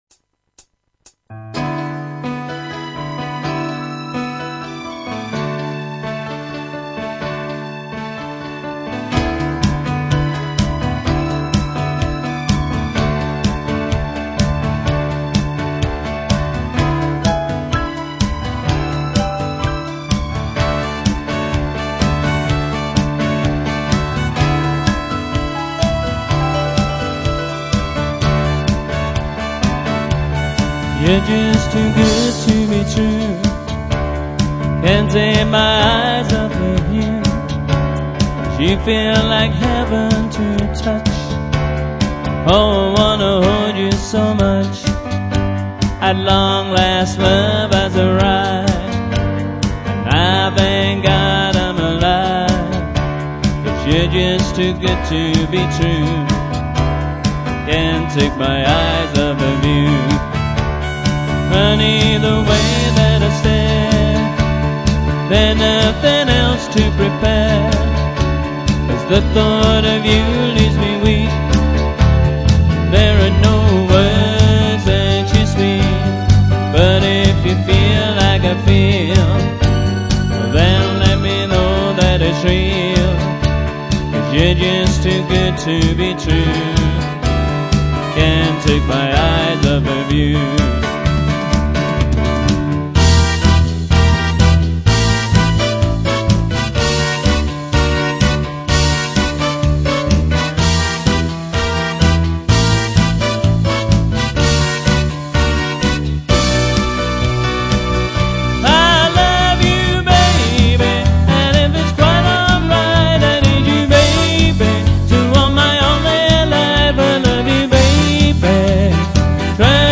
Backing track plus Roland RD700 piano, & vocals